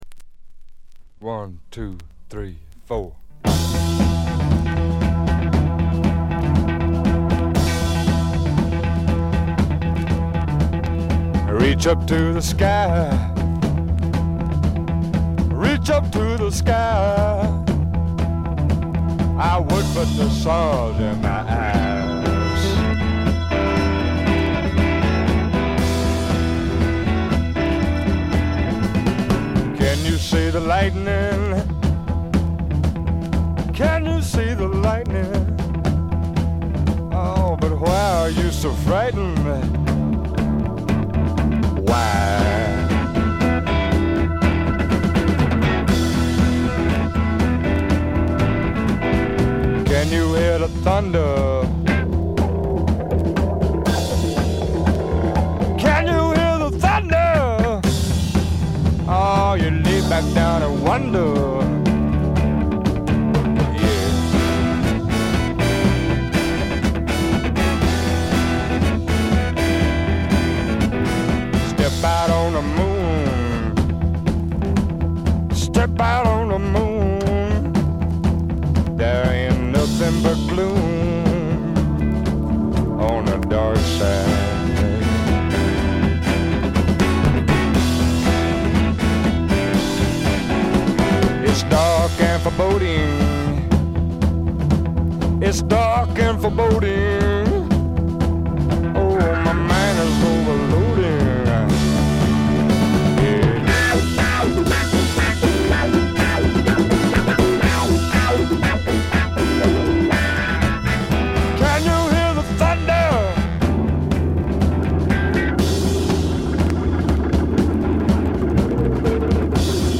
ところどころでチリプチ。散発的なプツ音。
ハードなファンキースワンプから甘いバラードまで、メンフィス録音スワンプ基本中の基本ですね。
試聴曲は現品からの取り込み音源です。